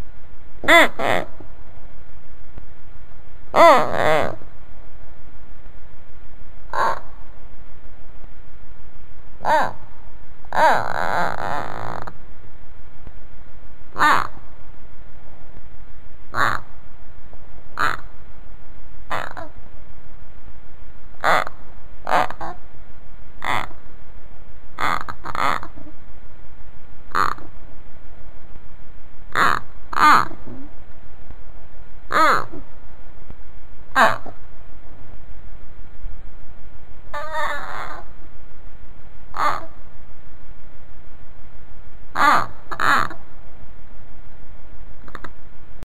Здесь собраны самые забавные и жизненные аудиозаписи: от веселого похрустывания корма до довольного попискивания.
Карбыш зовет своего хомяка